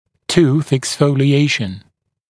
[tuːθ eksˌfəulɪ’eɪʃn][ту:с эксˌфоули’эйшн]выпадение зуба (в отн. молочных зубов)